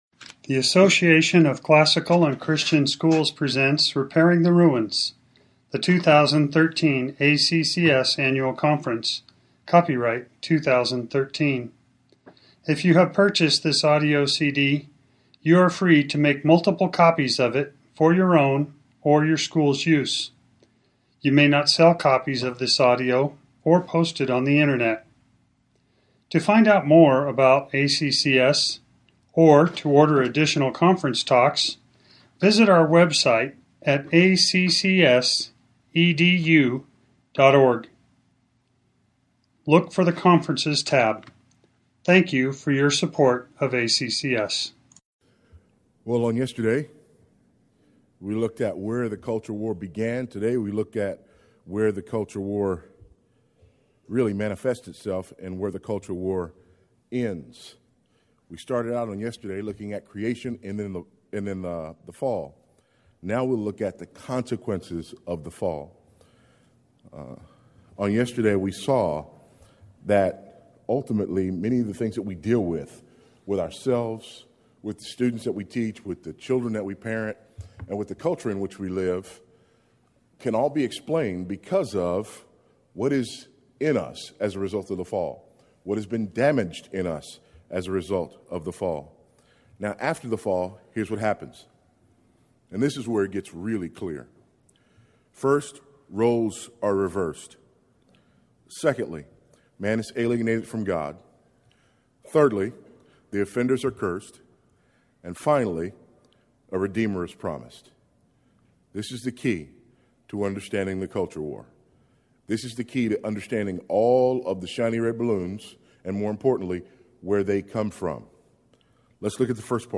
2013 Plenary Talk | 1:02:49 | All Grade Levels, Culture & Faith